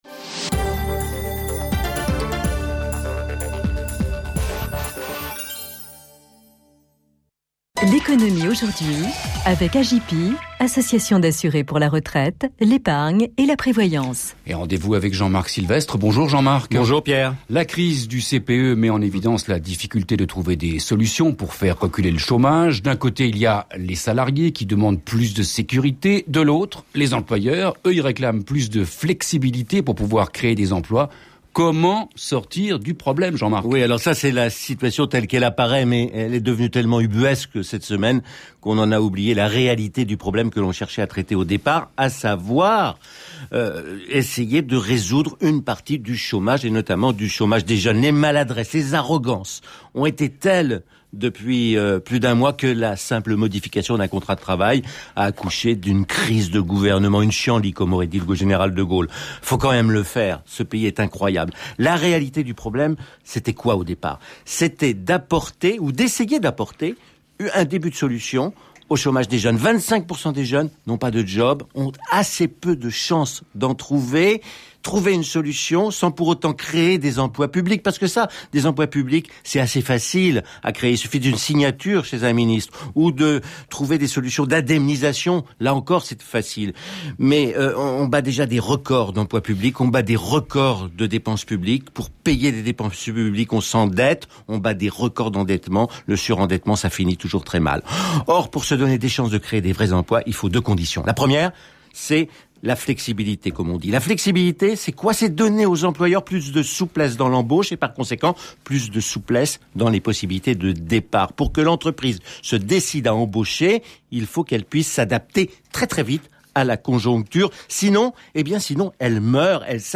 Ce matin (jeudi 6 avril) sur France Inter, Jean-Marc Sylvestre sévit comme d’habitude lors de sa chronique l’économie aujourd’hui. Il parle du CPE, bien sûr, c’est la mode.